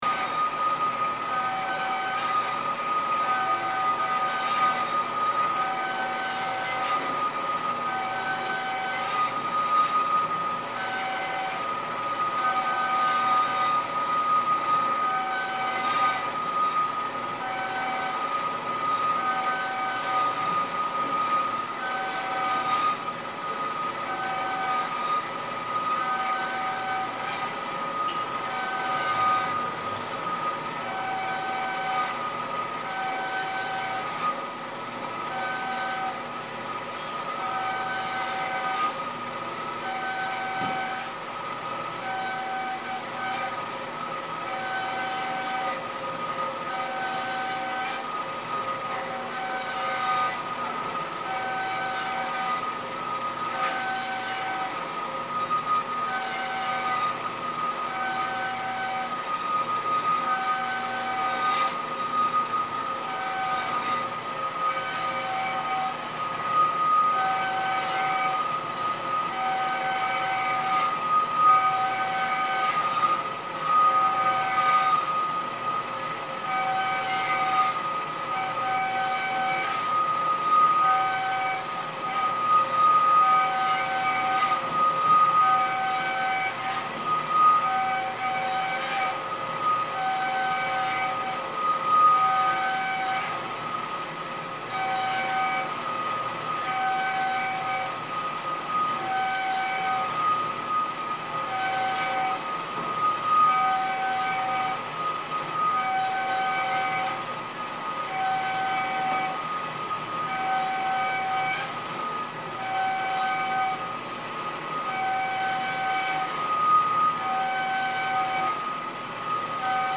This is a machine shop in Mullae-dong recorded through its doors open wide to the street.